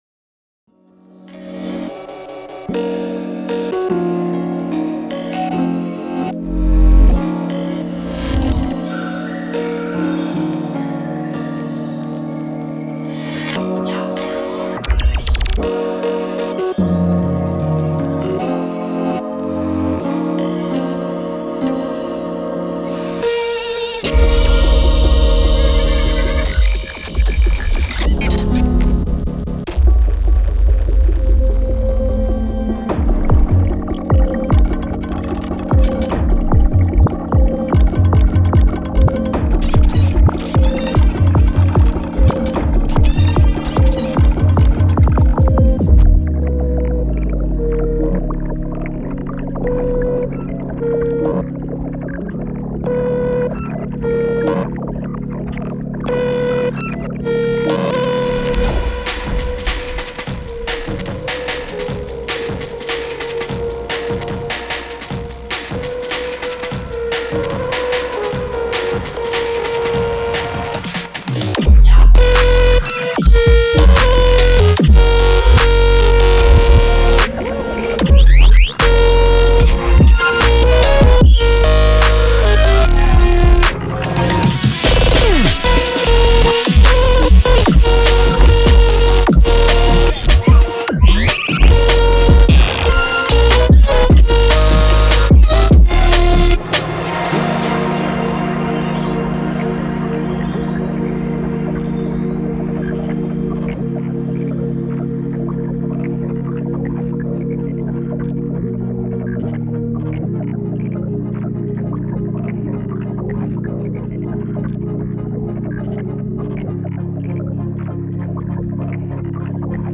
Bpm : 149-155
Key : F#/ D#m
I like the glitchy and ominous riffs at the beginning.
Drum N Bass